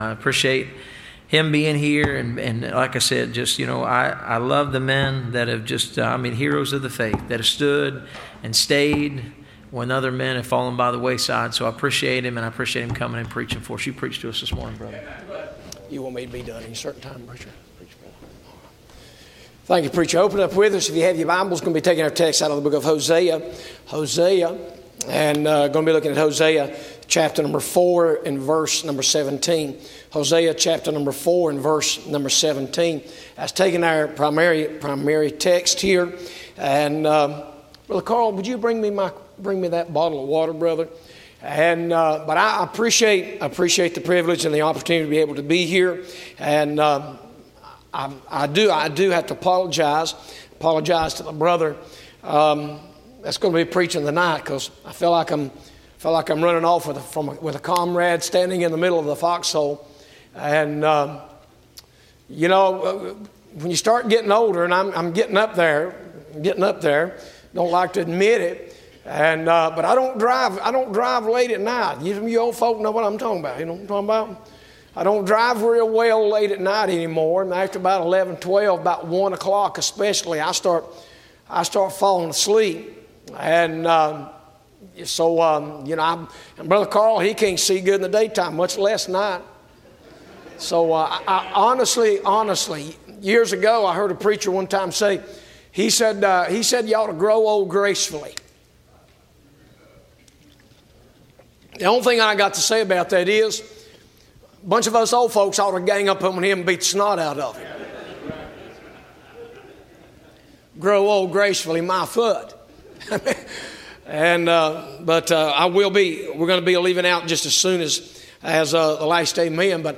Service Type: Bible Conference